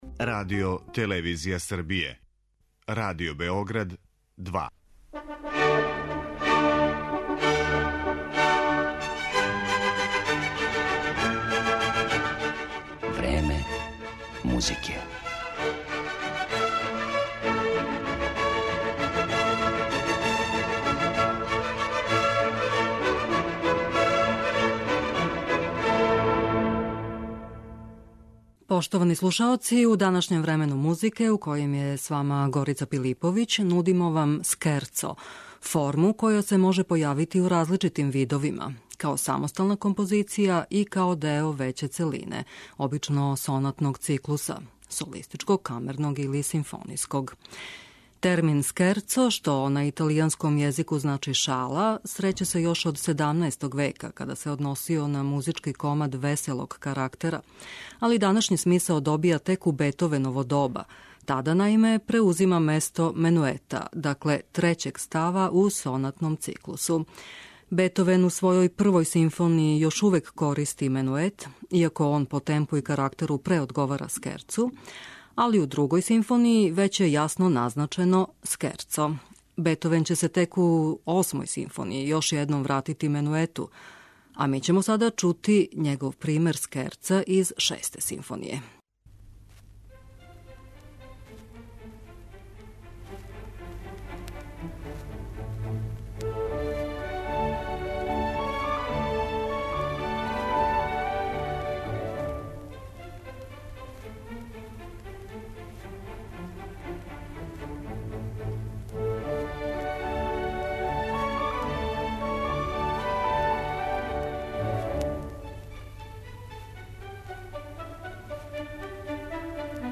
Различити примери скерца